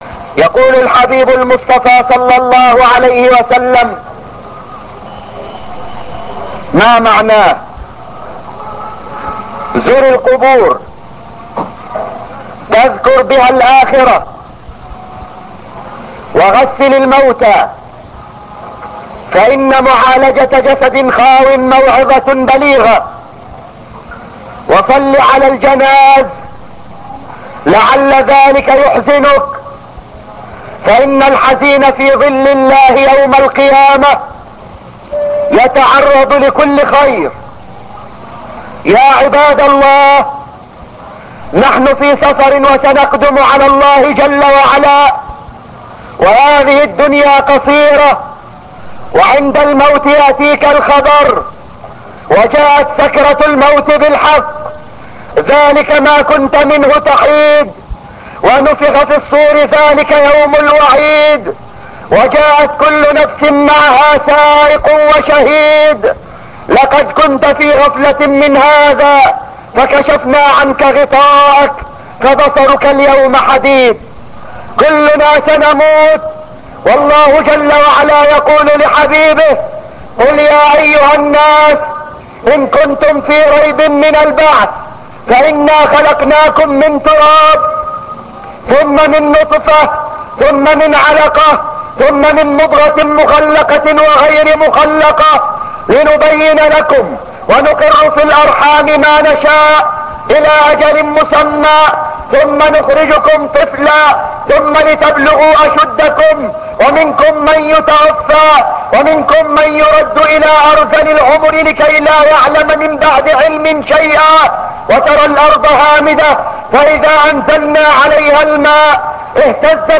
Sample of his speeches (2.5 Minutes)